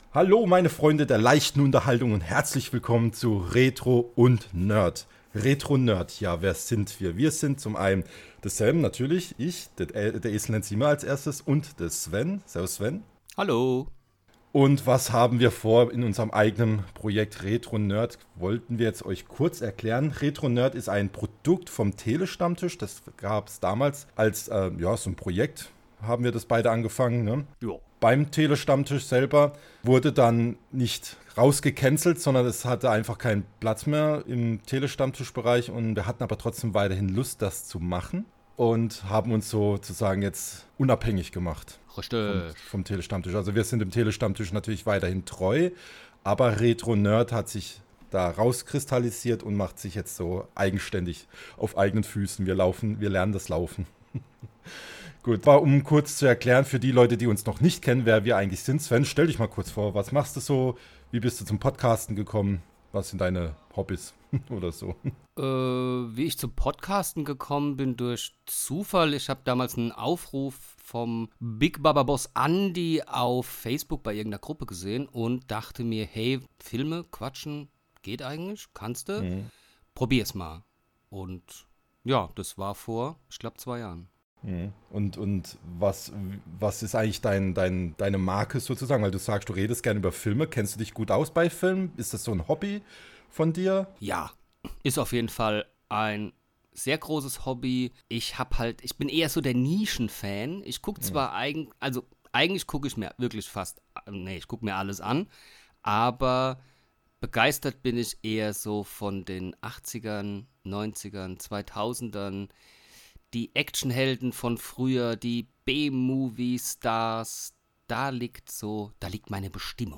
Ein Pfälzer und ein Badner